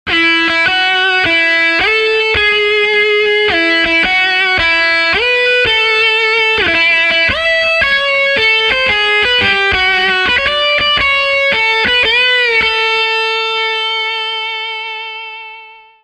Download Birthday sound effect for free.